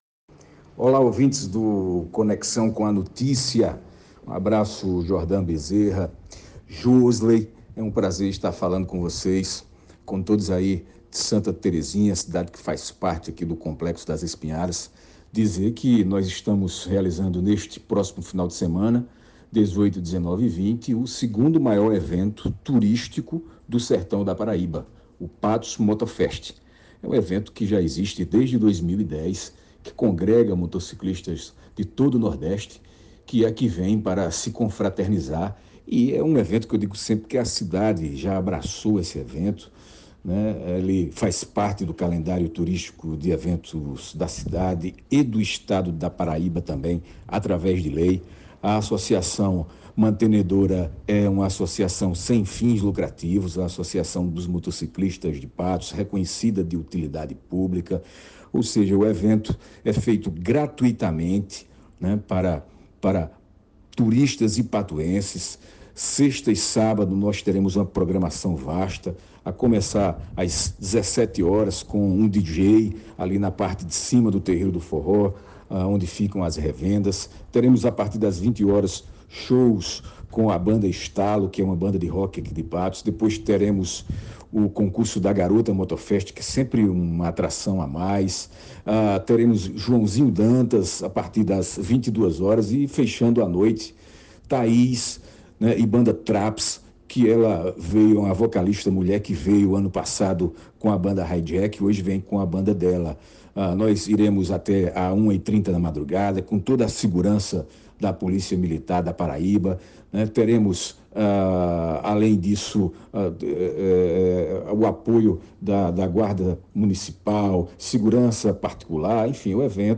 durante a sua participação no Jornal Conexão com a Notícia, transmitido pela Rádio Conexão FM de Santa Terezinha (104,9)